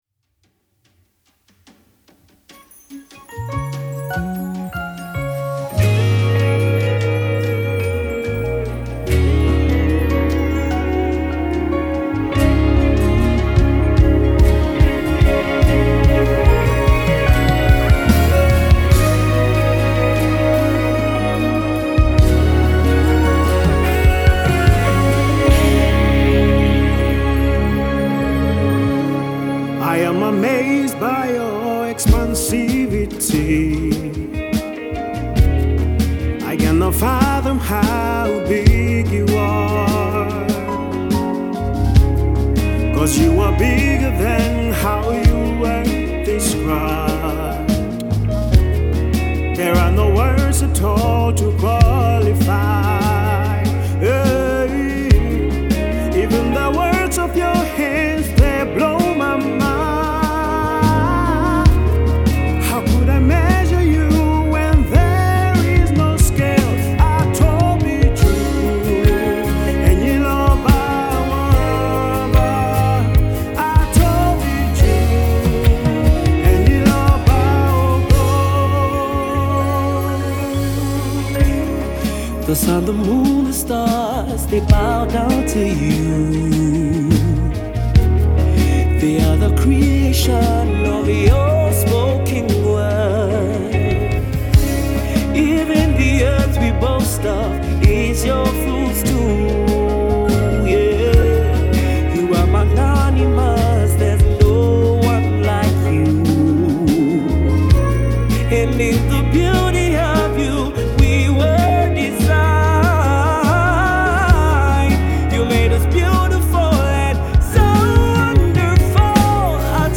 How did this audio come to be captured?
live- recorded